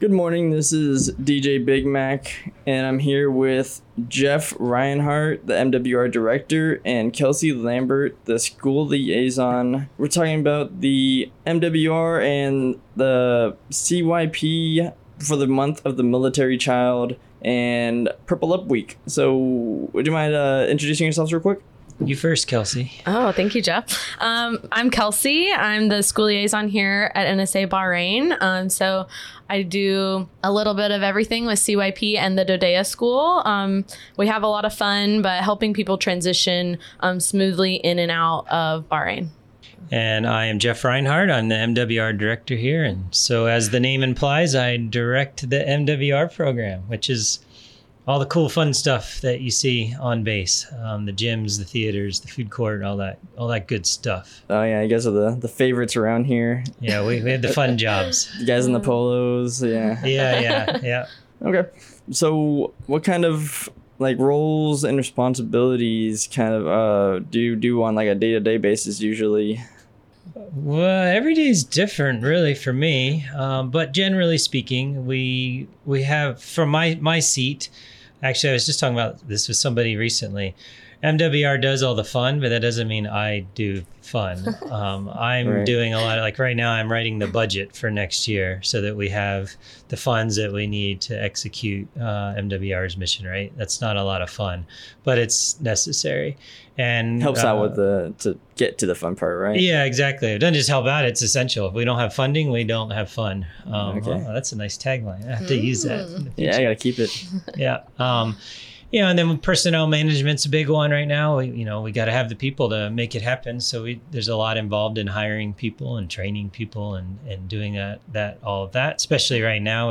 MWR Interview